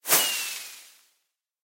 launch1.mp3